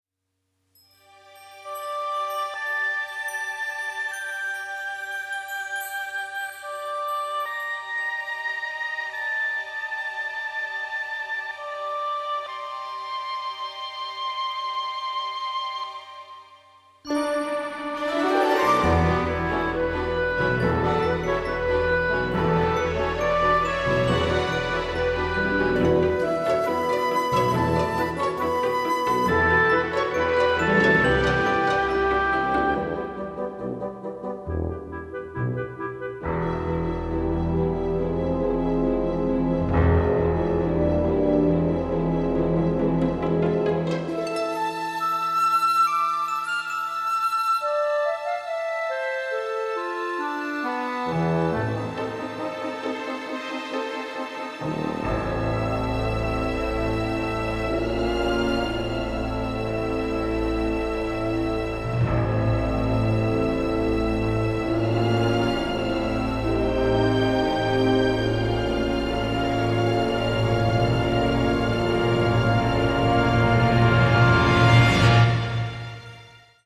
appropriately family friendly music
(Original Score)